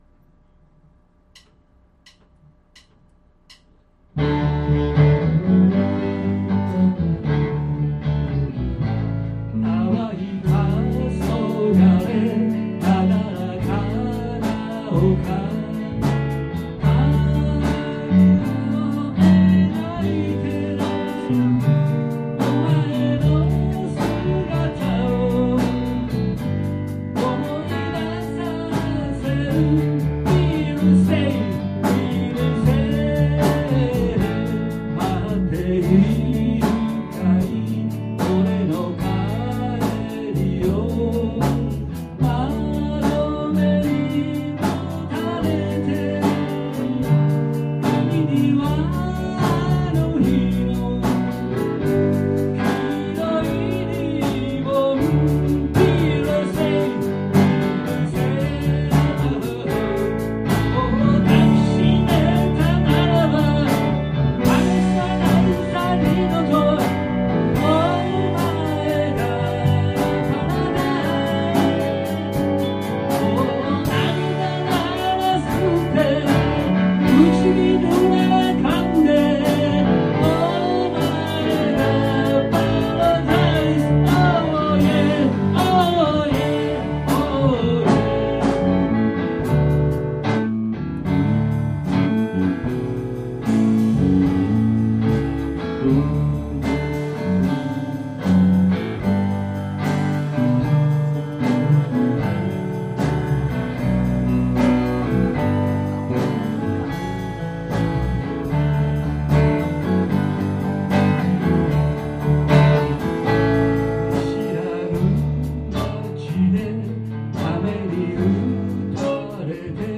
ボーカル・ギター
リードギター
キーボード・ボーカル
ドラムス
ベースギター